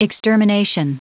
Prononciation du mot extermination en anglais (fichier audio)
Prononciation du mot : extermination